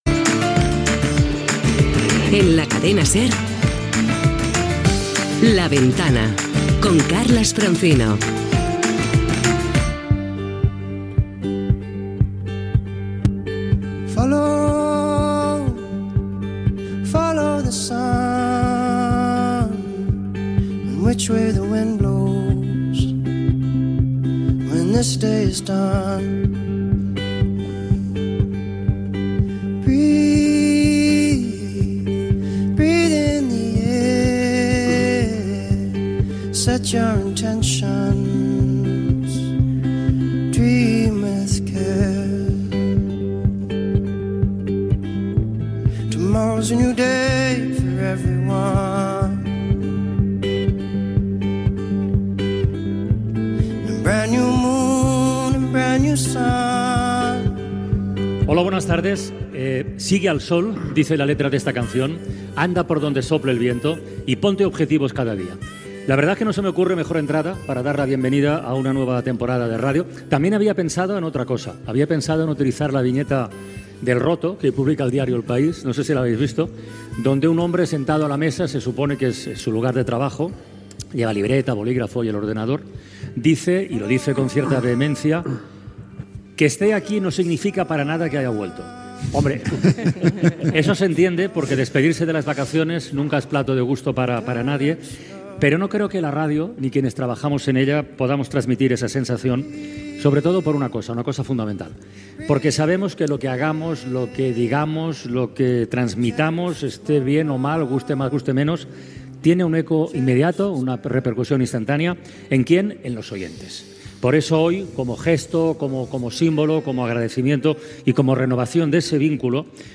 Careta del programa, cançó, portada del primer programa de la temporada 2025-2026, des del Círculo de Bellas Artes de Madrid.
Entreteniment